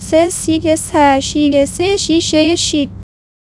persian-tts-female-GPTInformal-Persian-vits